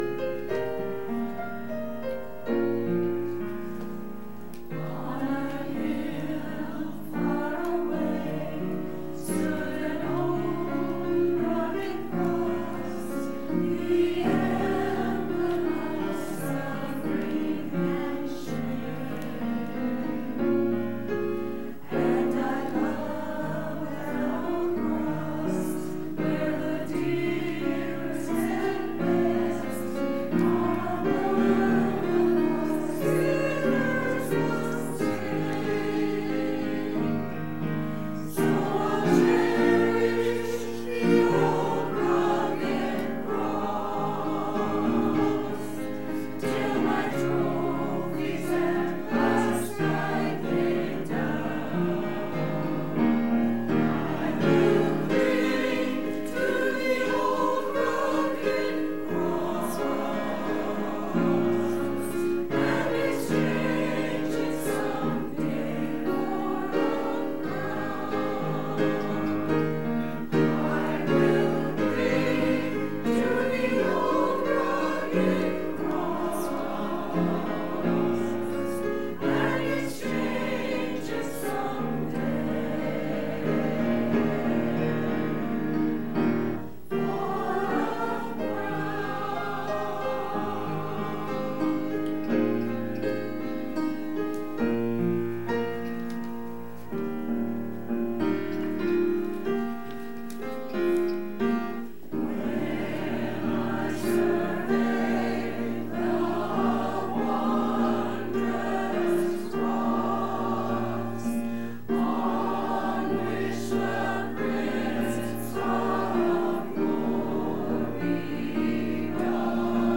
Church Choir – The Old Rugged Cross 04.19.19
To hear the church choir praise God with music please click below.